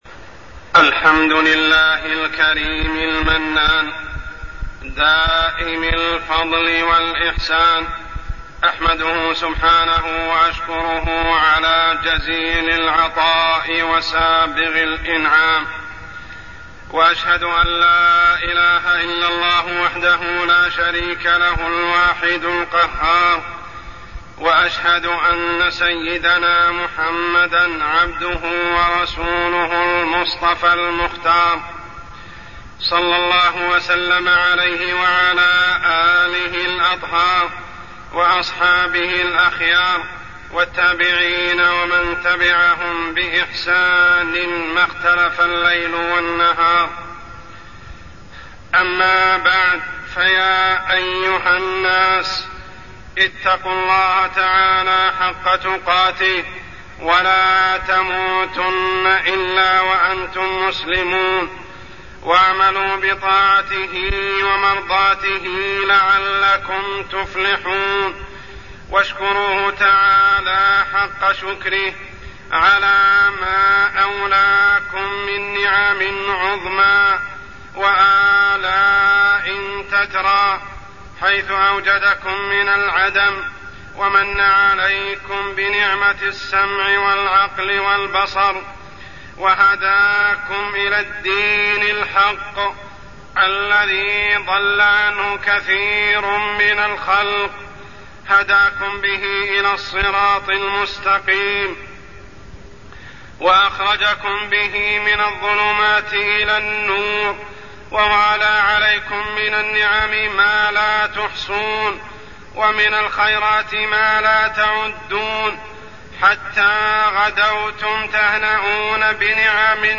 تاريخ النشر ٤ ربيع الأول ١٤٢٠ هـ المكان: المسجد الحرام الشيخ: عمر السبيل عمر السبيل قلة شكر المنعم